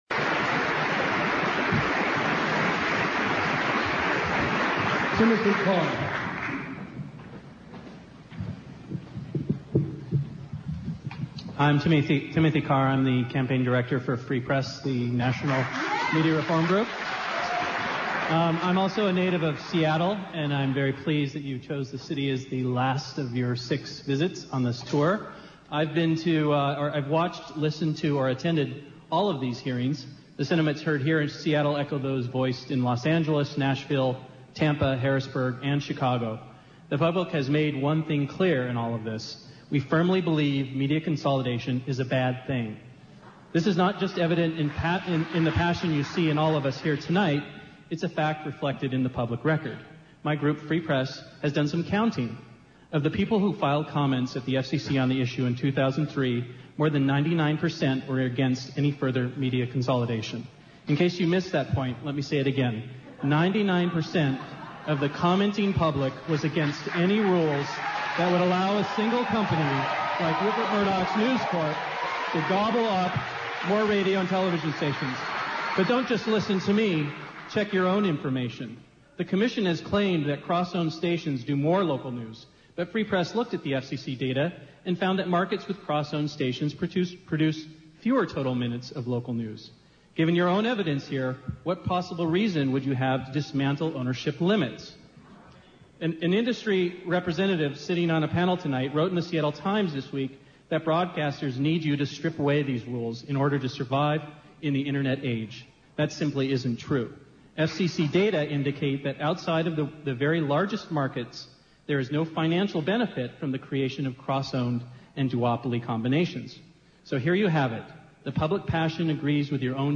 Hear audio from the sixth and final public hearing held on 9/9/07 on the FCC's current process to further deregulate the media. A series of 2-minute speeches are aired from the public comment period. KPFA, 94.1FM in Berkeley, aired the proceedings live.